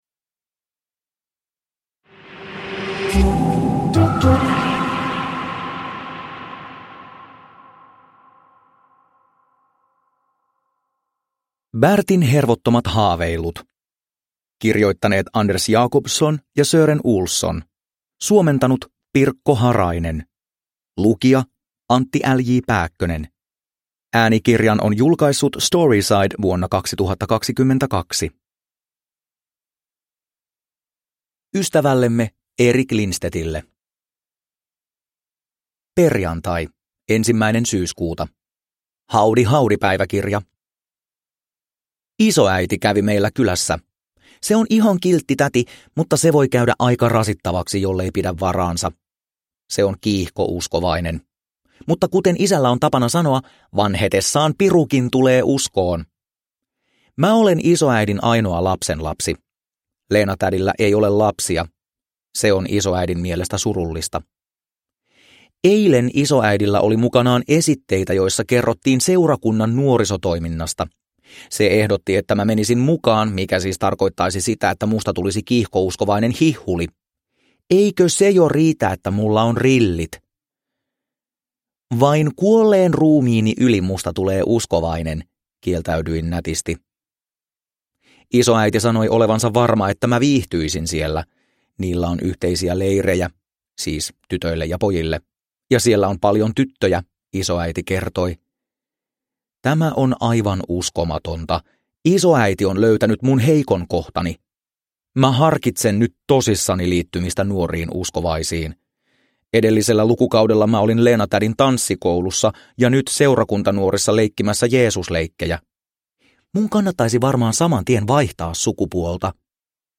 Bertin hervottomat haaveilut – Ljudbok – Laddas ner